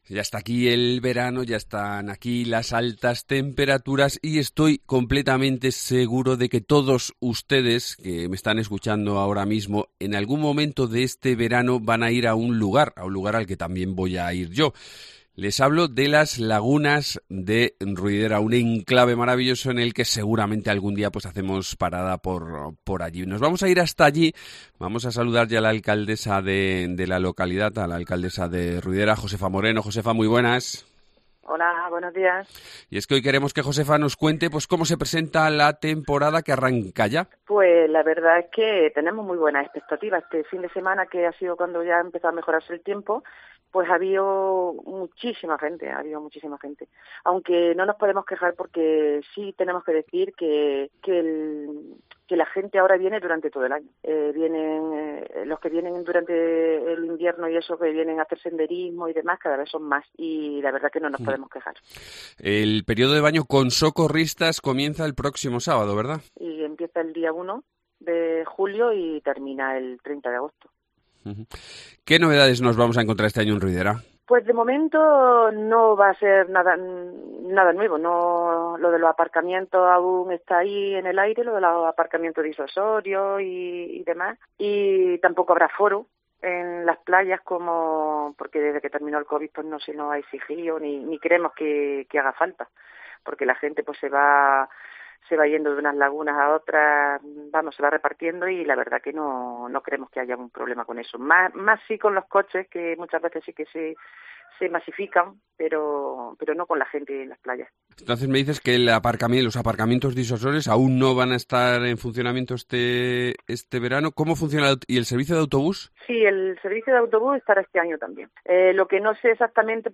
Josefa Moreno, alcaldesa de Ruidera